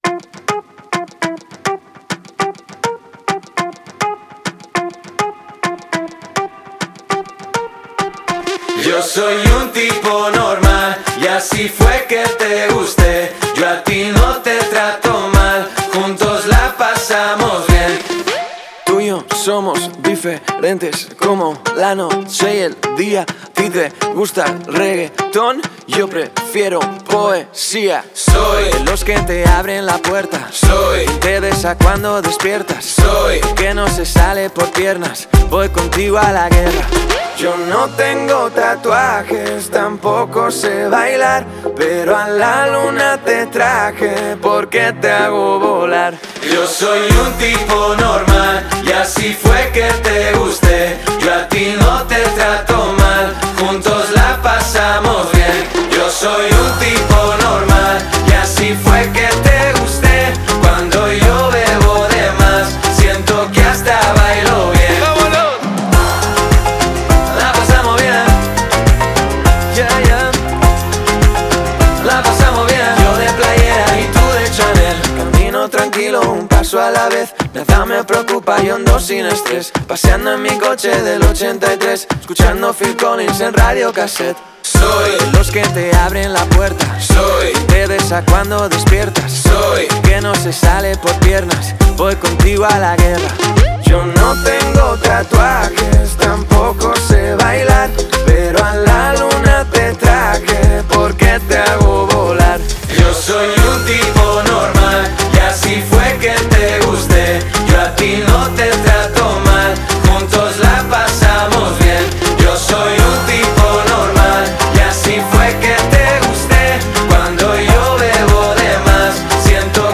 энергичная поп-песня